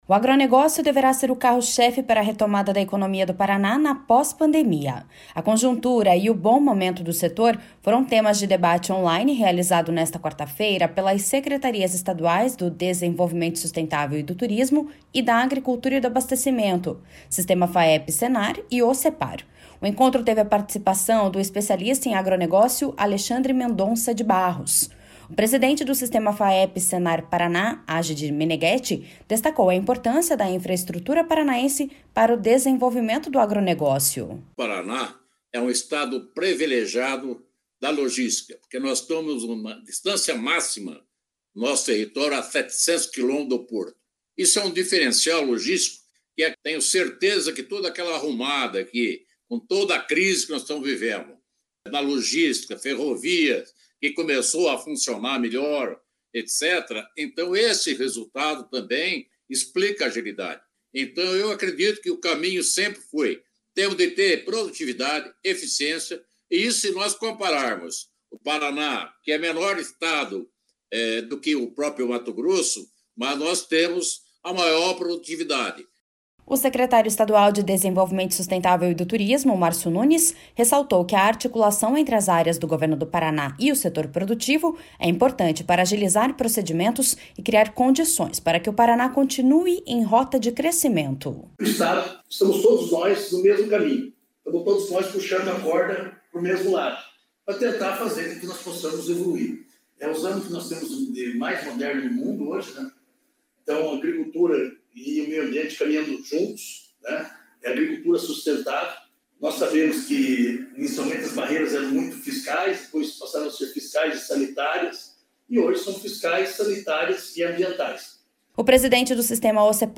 O secretário estadual de Desenvolvimento Sustentável e do Turismo, Márcio Nunes, ressaltou que a articulação entre as áreas do Governo do Paraná e o setor produtivo é importante para agilizar procedimentos e criar condições para que o Paraná continue em rota de crescimento.// SONORA MARCIO NUNES.//
A importância do setor de produção de proteína animal foi destacada pelo secretário estadual de Agricultura e Abastecimento, Norberto Ortigara.